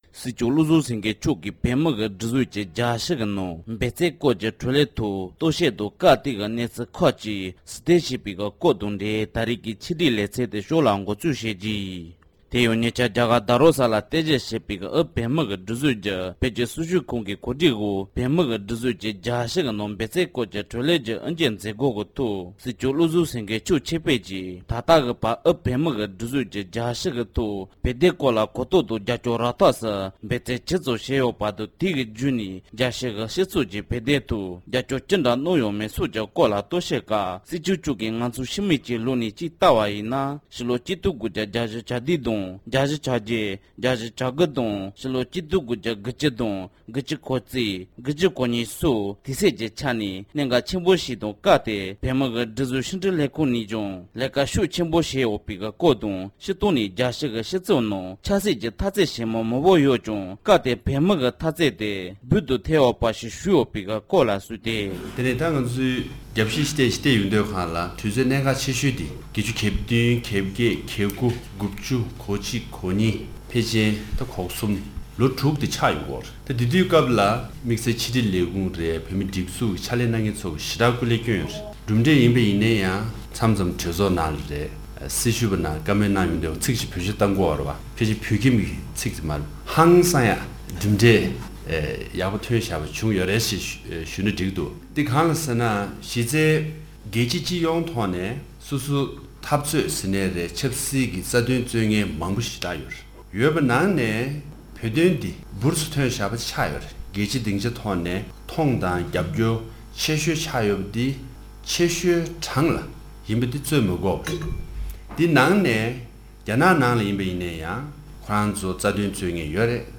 སྒྲ་ལྡན་གསར་འགྱུར། སྒྲ་ཕབ་ལེན།
༄༅༎ཐེངས་འདིའི་ཁམས་སྐད་ཀྱི་ཆེད་བསྒྲིགས་ལེ་ཚན་ནང་དུ།